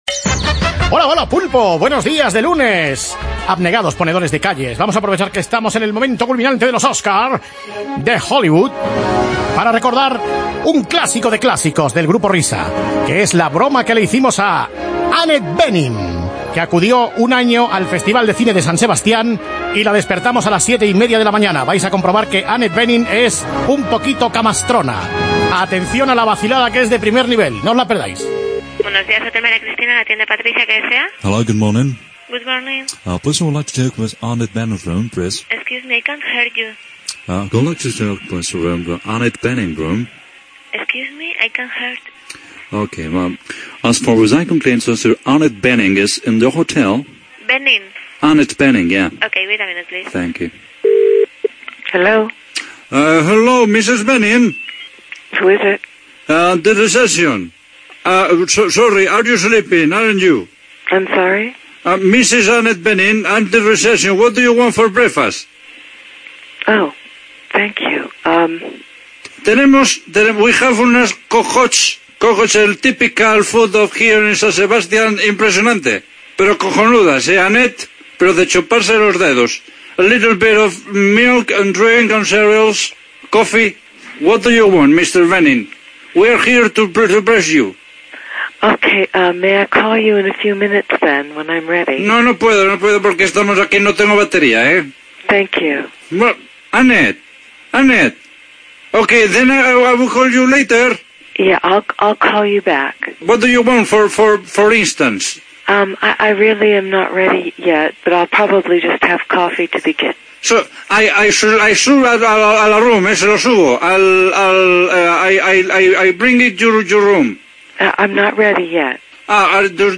Llamada a Annette Bening